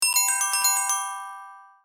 СМС сигнал для телефона